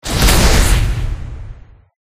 Collapse3.ogg